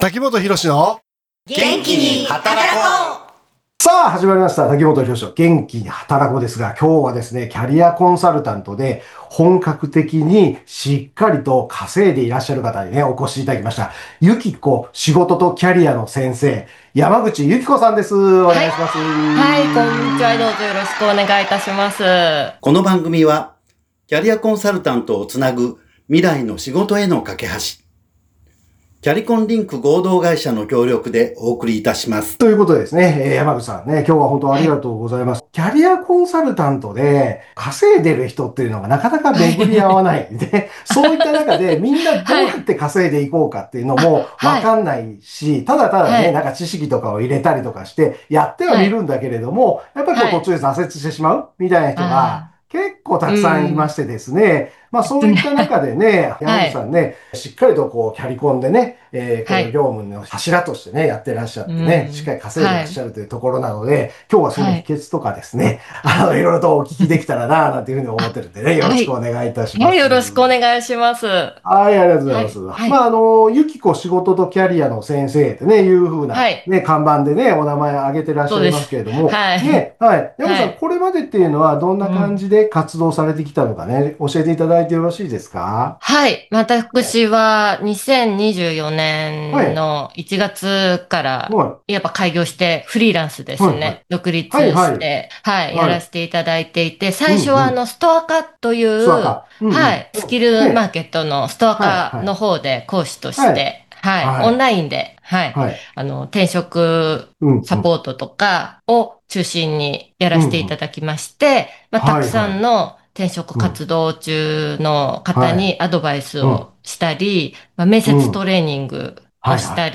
FM79.7京都三条ラジオカフェ202年01月08日放送分です。